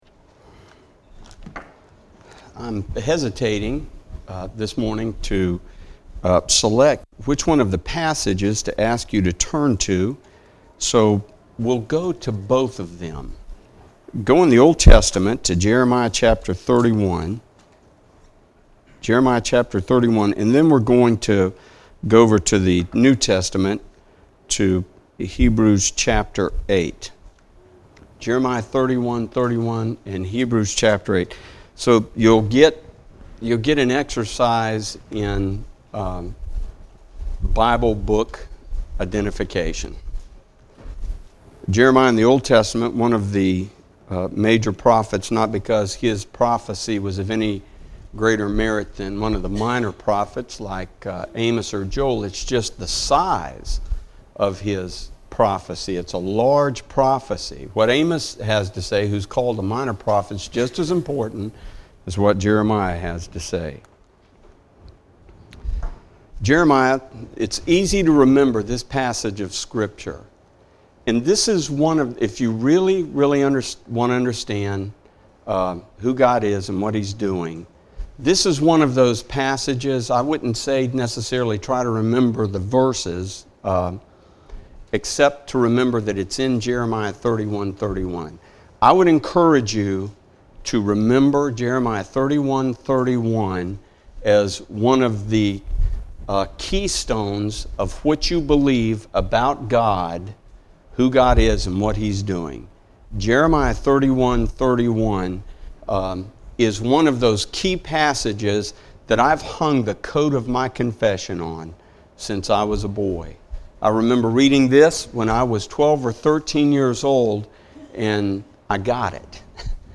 Passage: Jeremiah 31-31 Service Type: Sunday Morning